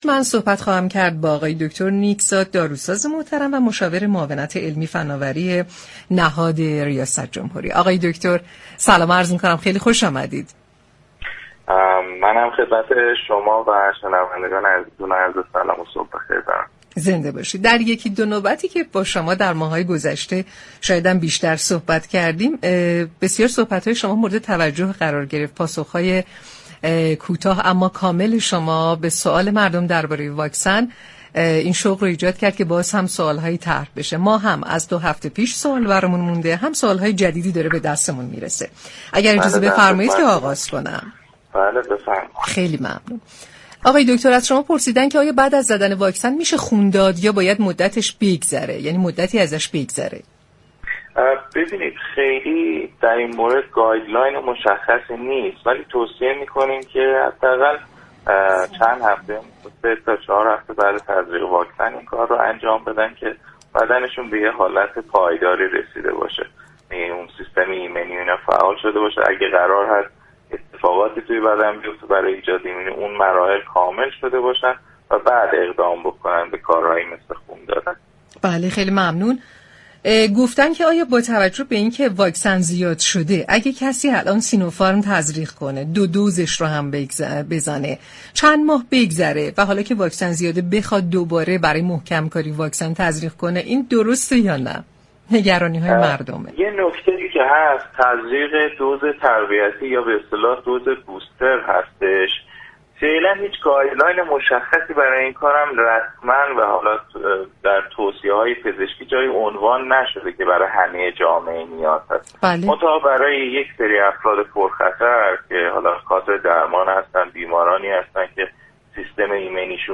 در گفتگو با تهران ما سلامت رادیو تهران